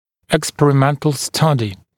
[ɪkˌsperɪ’mentl ‘stʌdɪ][икˌспери’мэнтл ‘стади]экспериментальное исследование